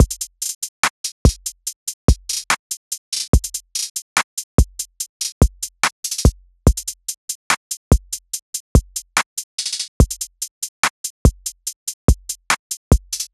SOUTHSIDE_beat_loop_red_full_01_144.wav